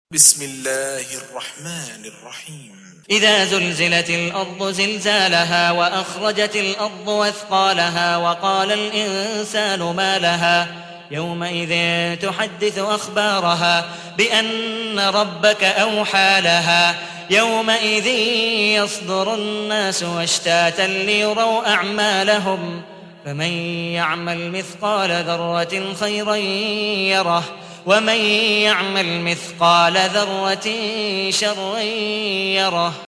99. سورة الزلزلة / القارئ